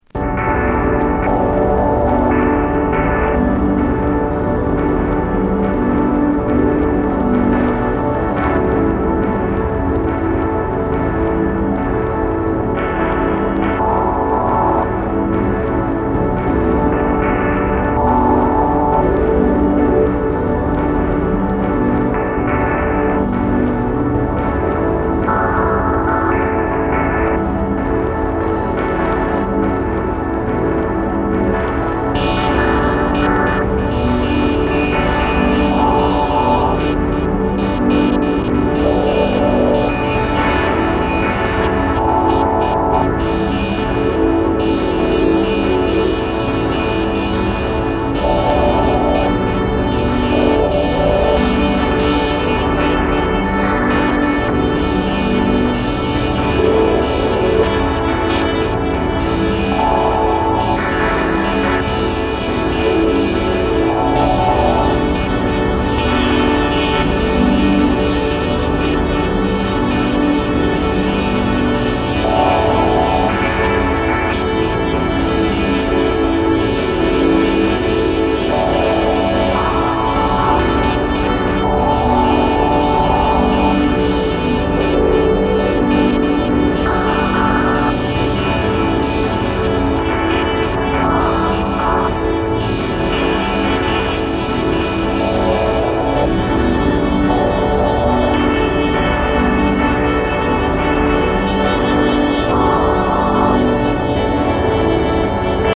壮大なアンビエンス